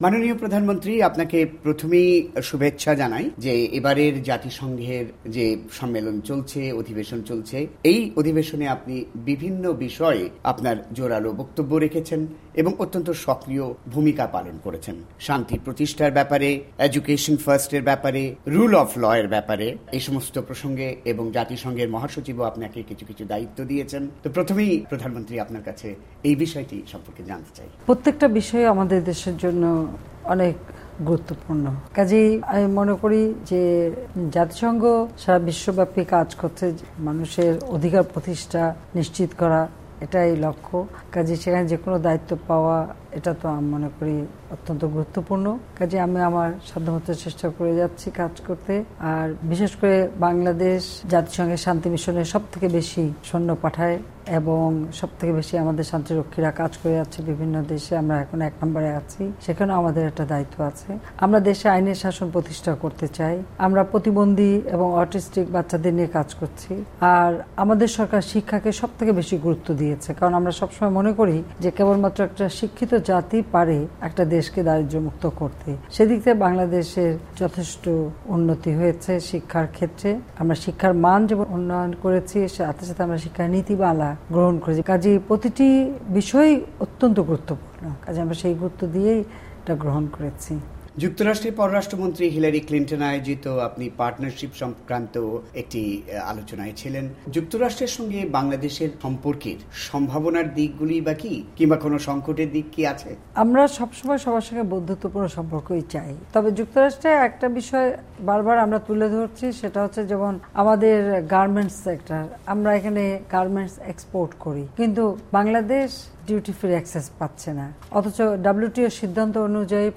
Bangladesh PM interview